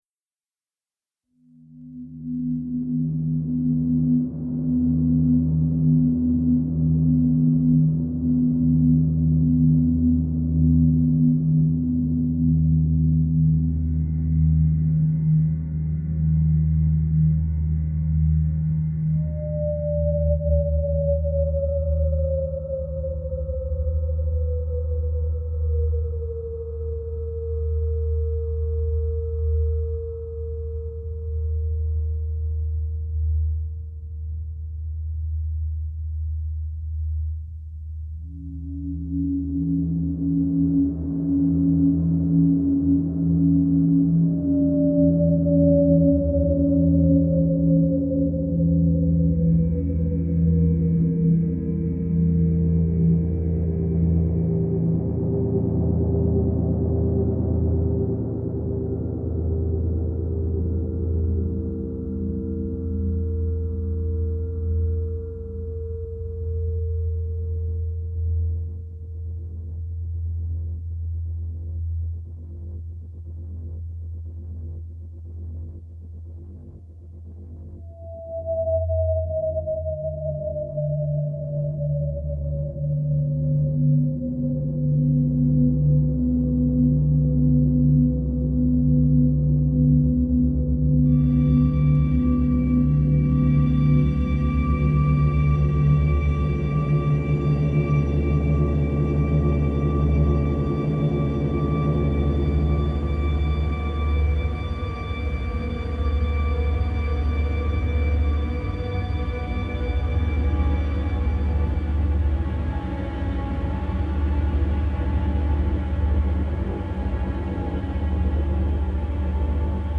Ljudspåret baseras på inspelningar utförda slumpvis dygnet runt i Vävaren under ett par veckor. Bland annat har byggnaden en hörbar ”stämton” som fortplantas i bjälklag och ventilation och sätter sin akustiska prägel på hela huset.